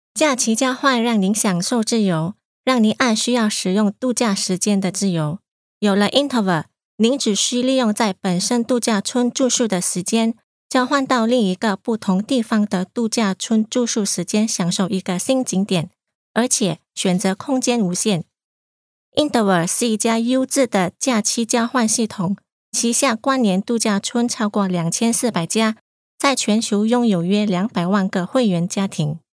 Professional female voice over artist from China.
Sprechprobe: Werbung (Muttersprache):